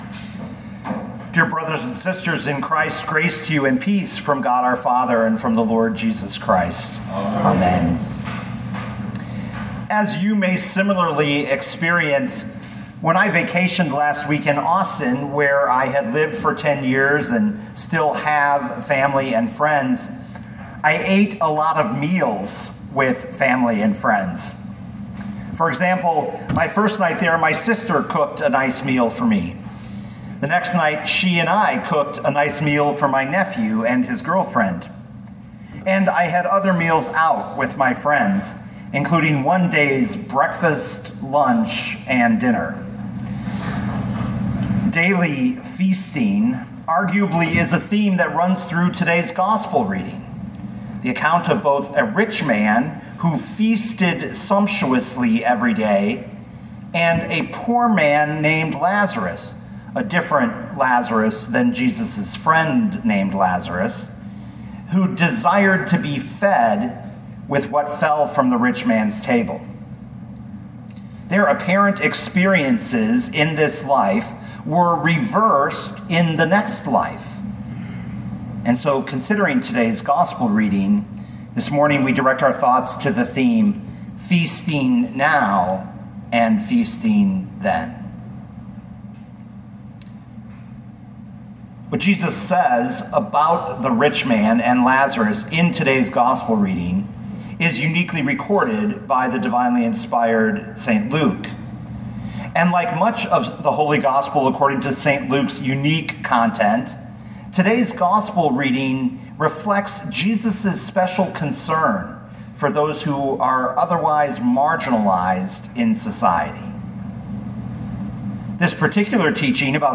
2022 Luke 16:19-31 Listen to the sermon with the player below, or, download the audio.